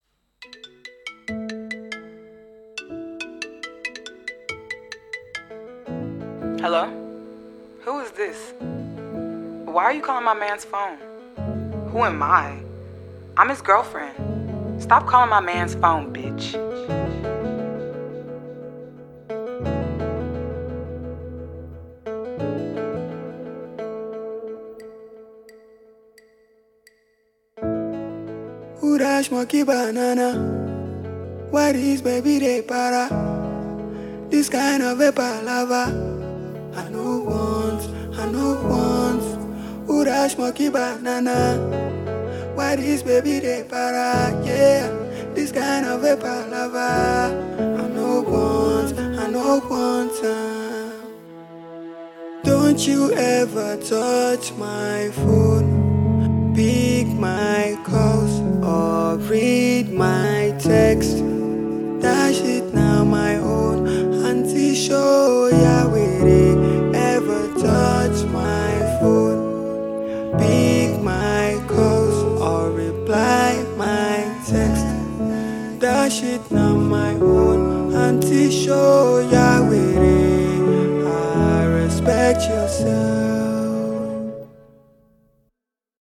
Super Talented Indigenous Rapper